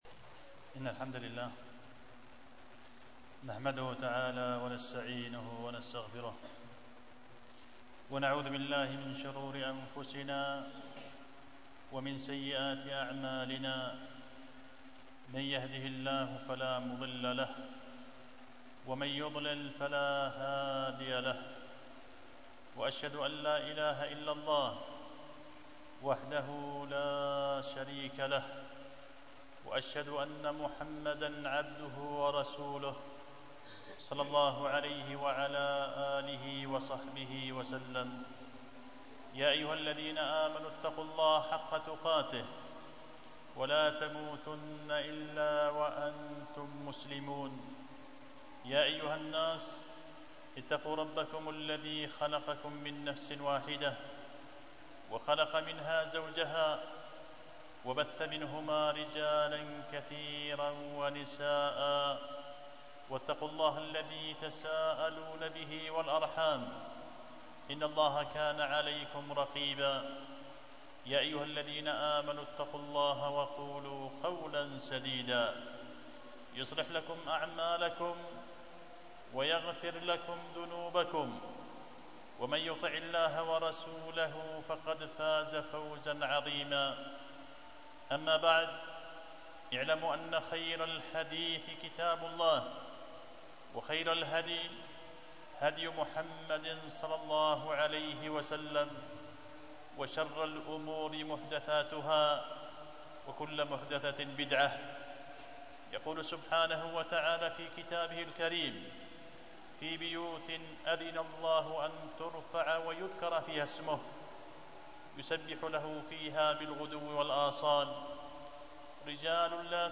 خطبة جمعة بعنوان إظهار النفائس لخير المجالس